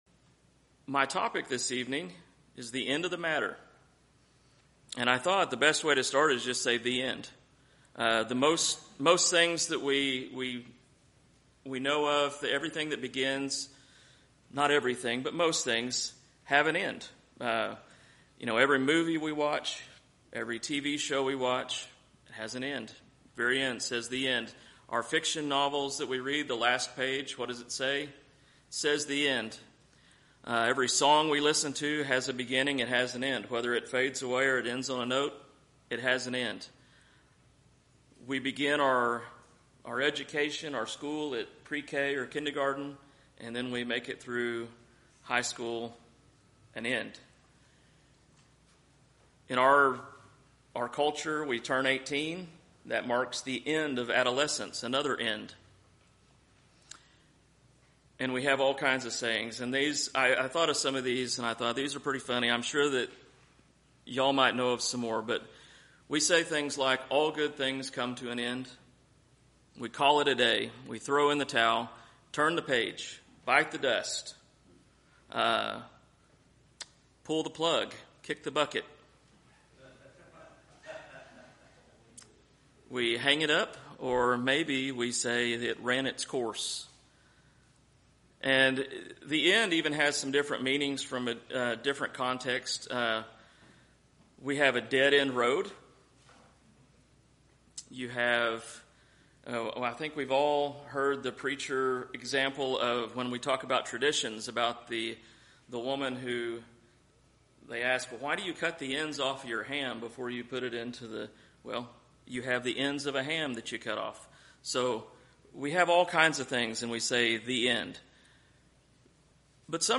A Faithful New Testament Church whose mission is to share the love and gospel of Jesus Christ with our community and the world.